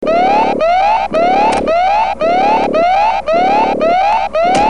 onekeyalarm.mp3